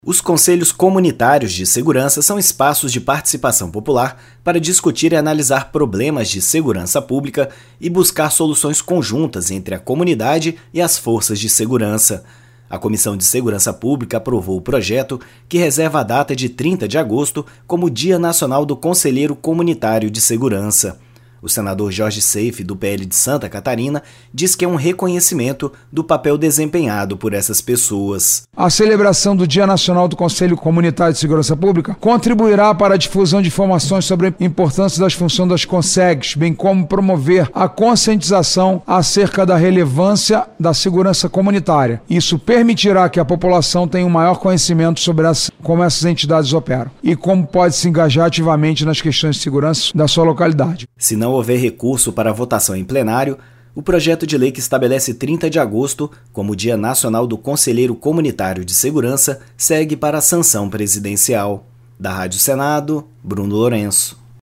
O senador Jorge Seif (PL-SC) diz que é um reconhecimento do papel desempenhado por essas pessoas na busca de soluções conjuntas entre a comunidade e as forças de segurança.